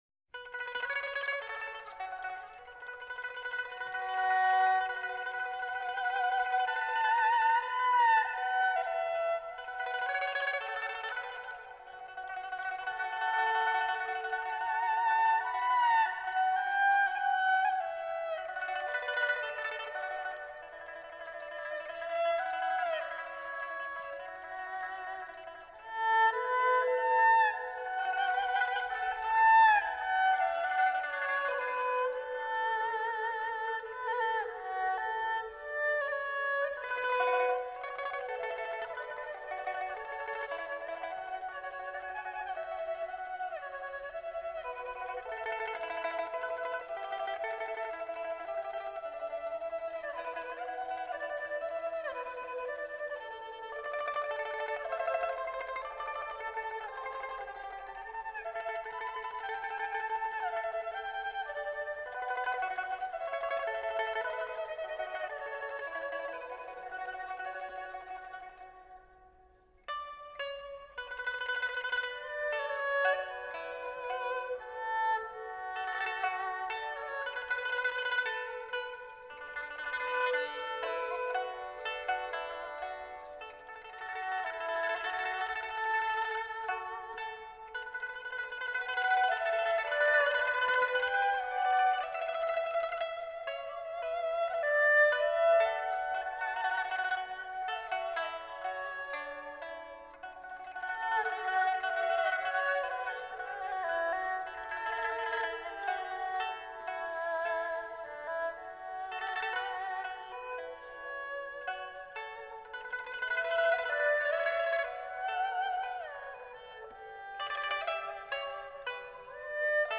自然 淡雅
琵琶演奏
二胡演奏